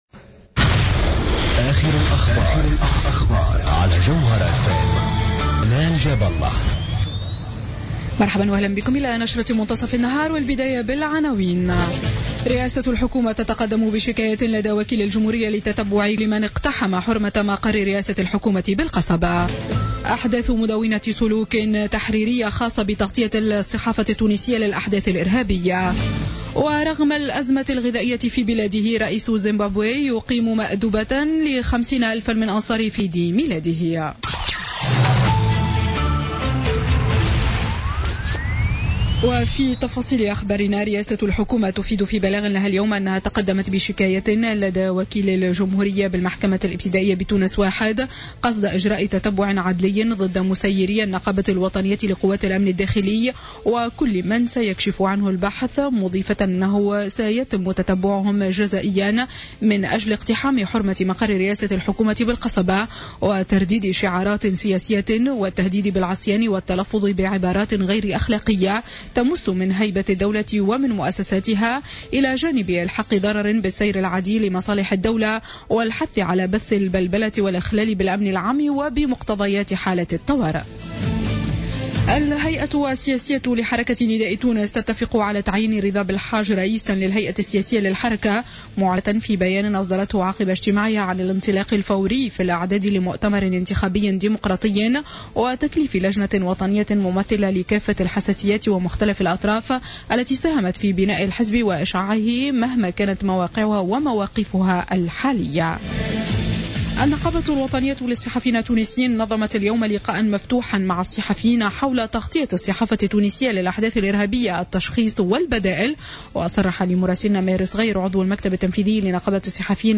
نشرة أخبار منتصف النهار ليوم السبت 27 فيفري 2016